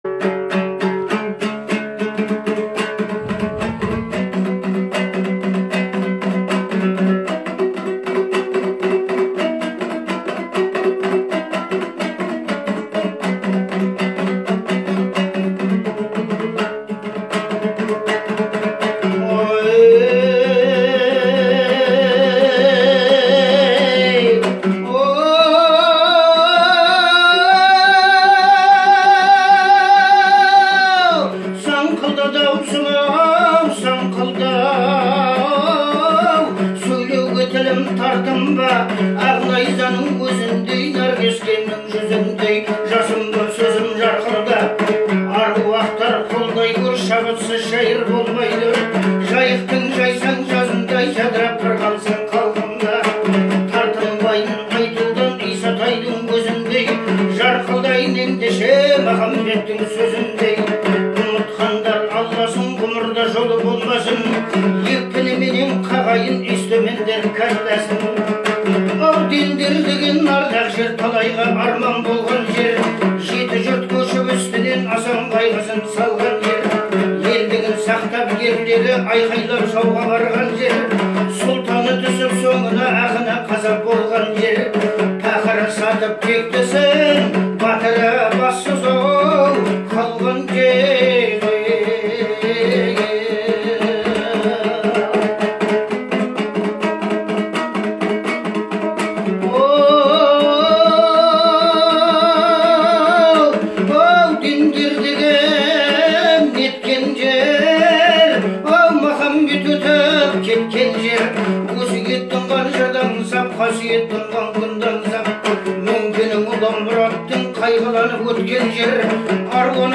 это песня в жанре казахской народной музыки
Настроение композиции — меланхоличное и ностальгическое